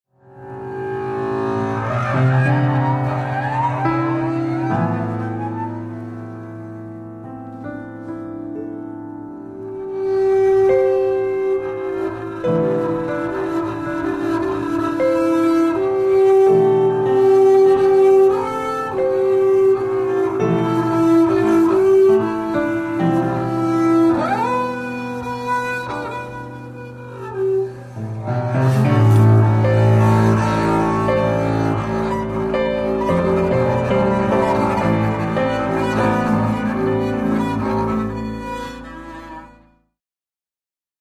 at Secret House Studio, Amsterdam
contrabajo
piano preparado